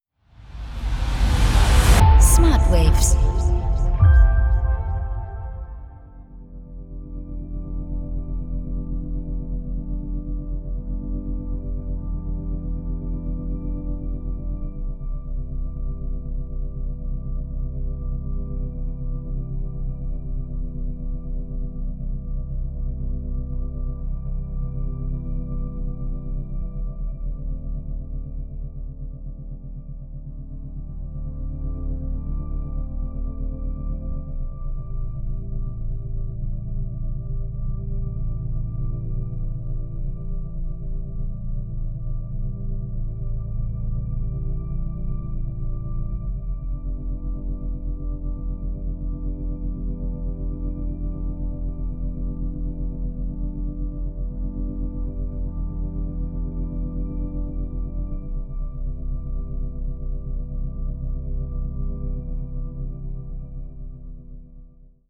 0,5-4 Hertz Delta Wellen Frequenzen